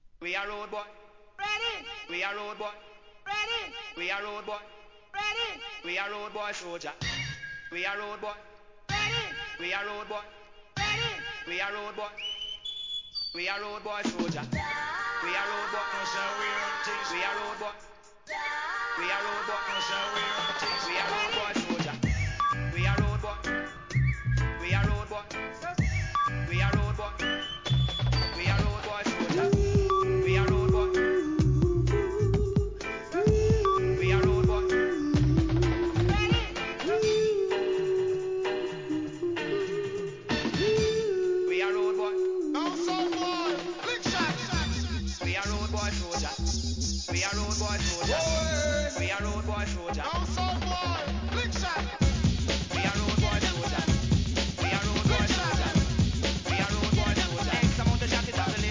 HIP HOP〜ラガ・ジャングルまで斬新なブレイクビート・サウンド満載!!!